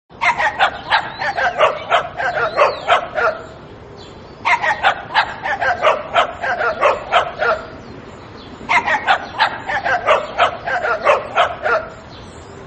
جلوه های صوتی
دانلود صدای حیوانات 41 از ساعد نیوز با لینک مستقیم و کیفیت بالا
برچسب: دانلود آهنگ های افکت صوتی انسان و موجودات زنده دانلود آلبوم مجموعه صدای حیوانات مختلف با سبکی خنده دار از افکت صوتی انسان و موجودات زنده